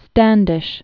(stăndĭsh), Miles or Myles 1584?-1656.